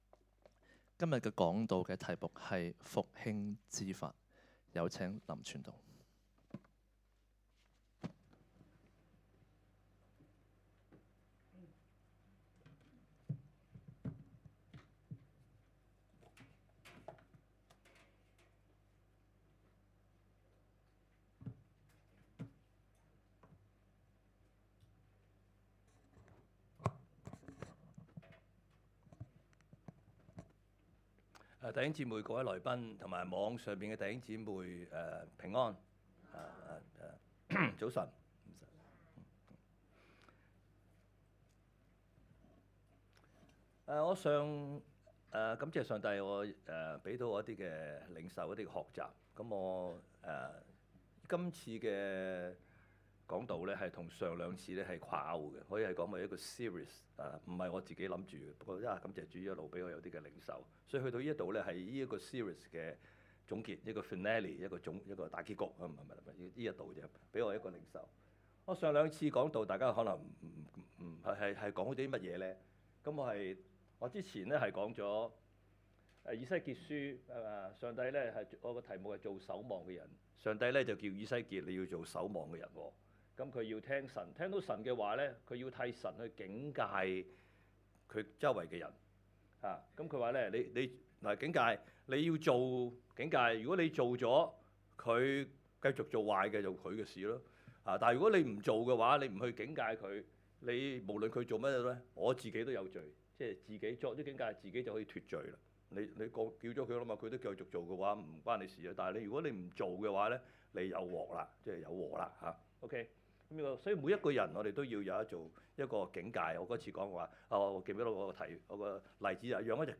2026年4月11日及12日講道